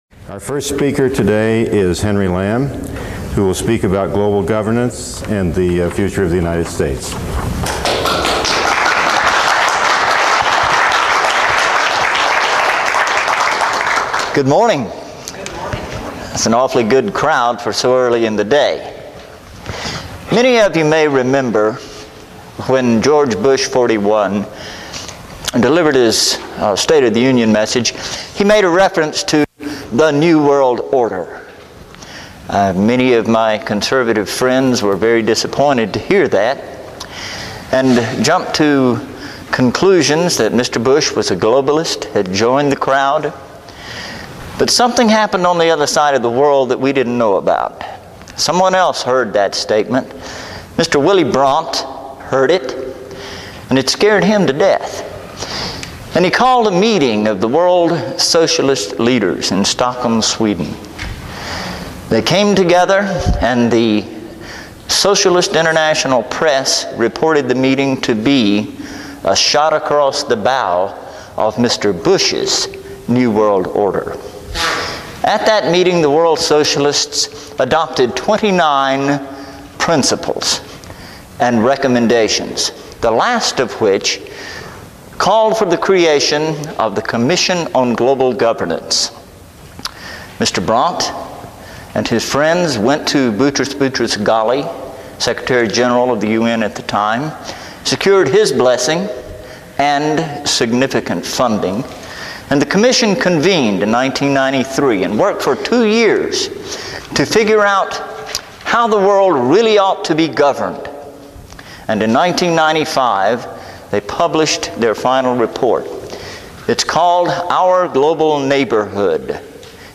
Lecture
at the 20th Annual Meeting of the Doctors for Disaster Preparedness held in Colorado Springs, Colorado